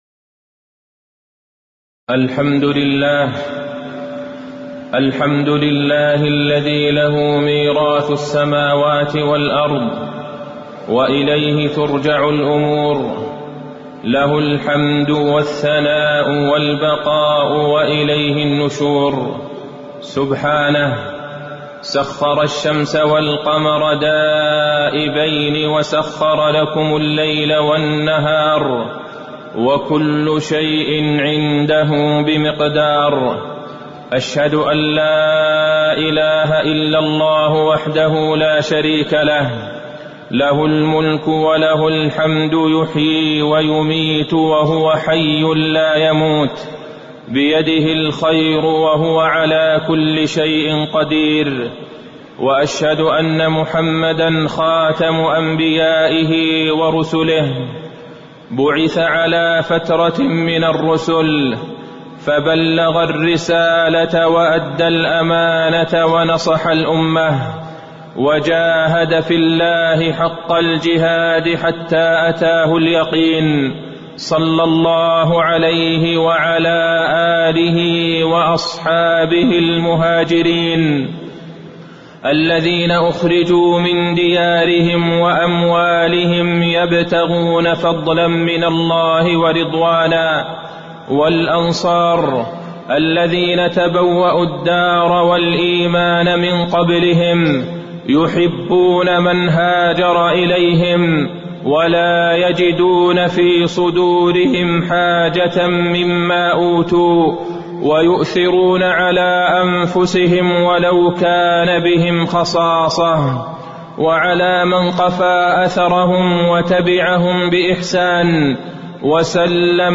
تاريخ النشر ٢ محرم ١٤٣٩ هـ المكان: المسجد النبوي الشيخ: فضيلة الشيخ د. عبدالله بن عبدالرحمن البعيجان فضيلة الشيخ د. عبدالله بن عبدالرحمن البعيجان اغتنام العمر بالطاعات